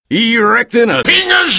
engineer_autobuildingdispenser01.mp3